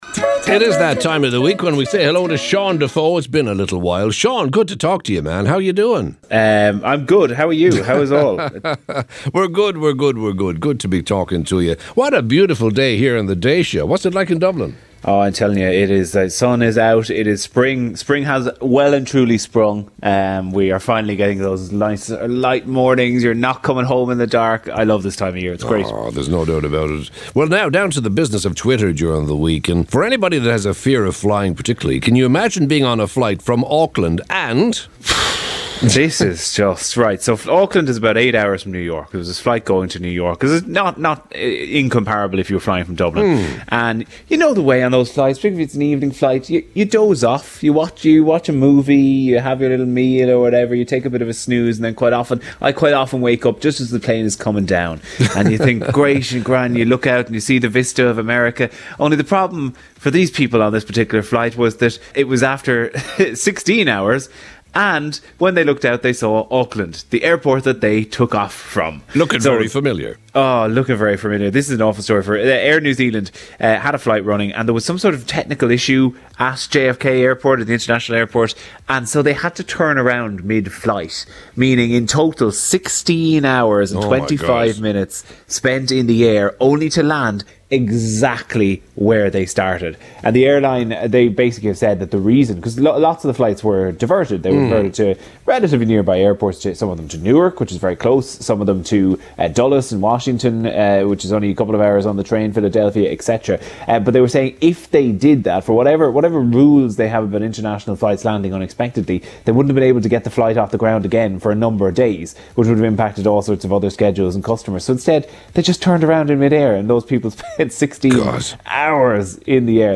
chats